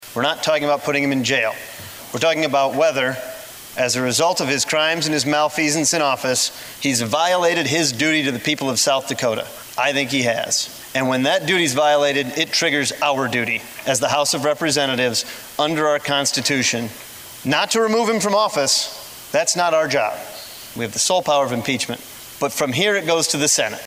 Mortenson said impeachment is not about taking away someone’s rights.